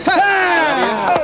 hahahh.mp3